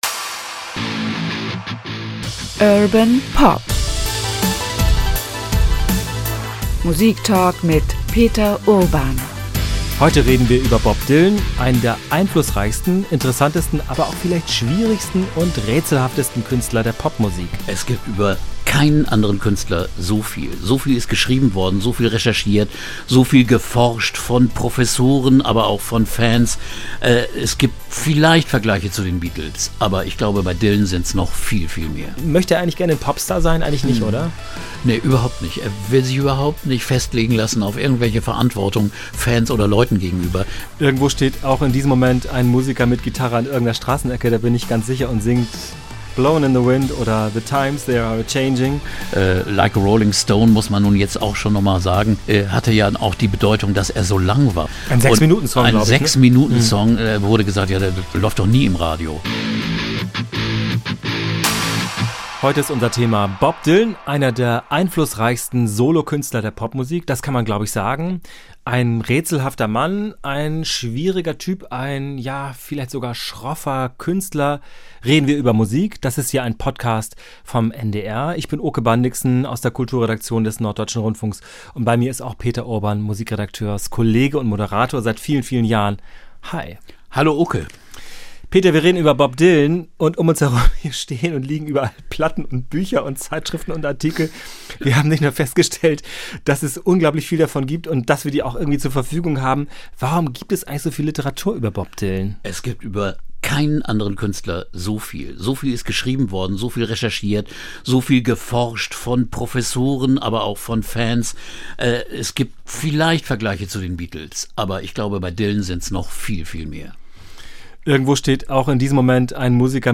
NDR Podcast Urban Pop über den Musiker Bob Dylan ~ Urban Pop - Musiktalk mit Peter Urban Podcast